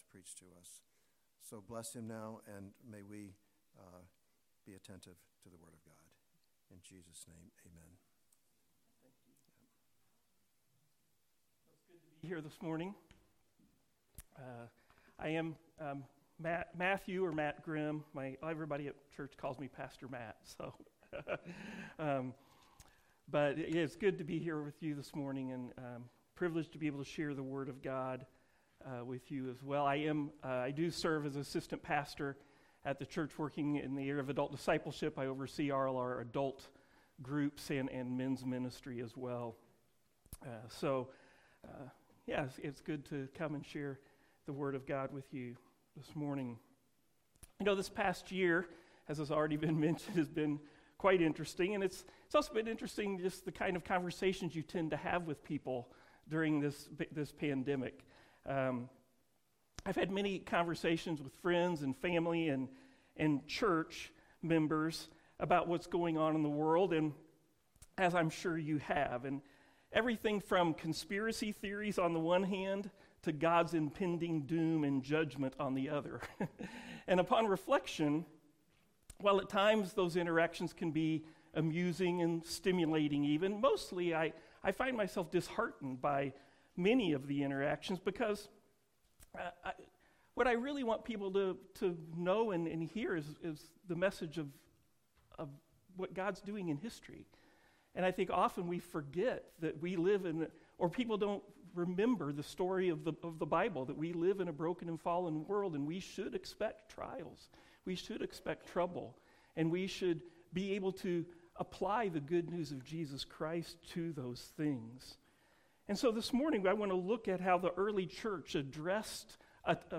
Sermons Passage: Acts 4:23-37 Services: Sunday Morning Service Download Files Notes Topics: Courage Previous Next